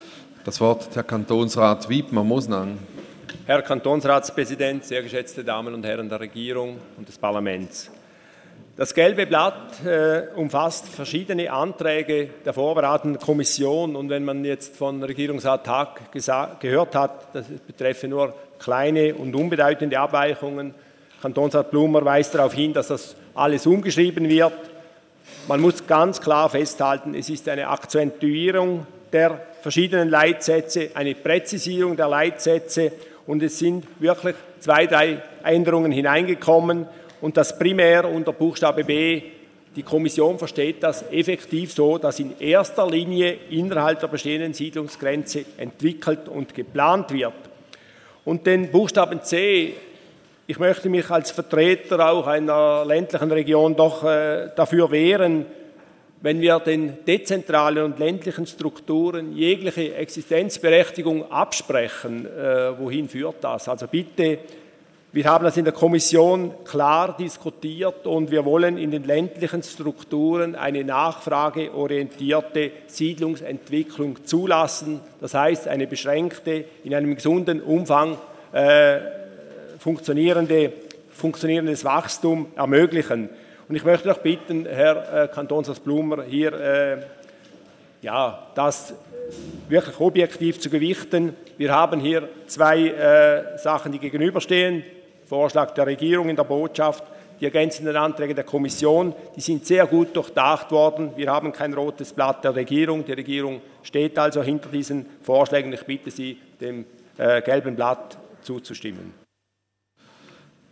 16.9.2015Wortmeldung
Session des Kantonsrates vom 14. bis 16. September 2015